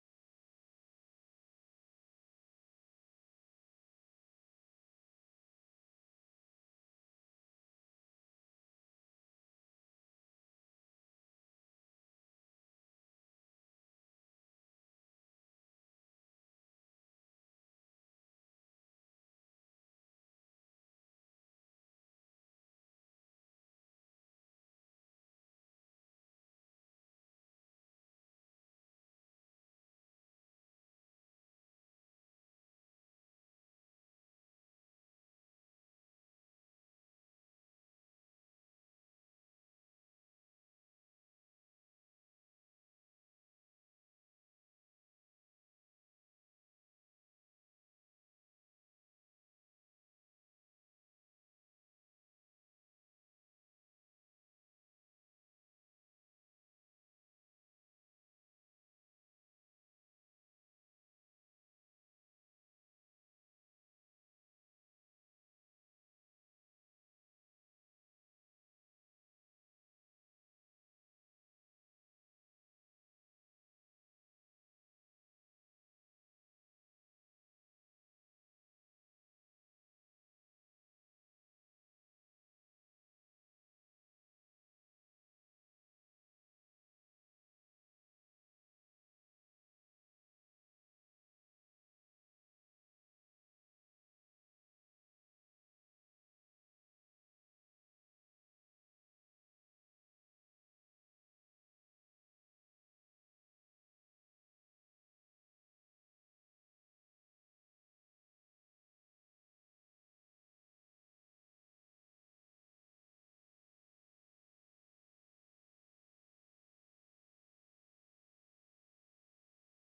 Эфир ведёт Ольга Бычкова